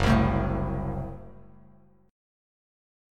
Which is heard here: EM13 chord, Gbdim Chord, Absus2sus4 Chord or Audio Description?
Gbdim Chord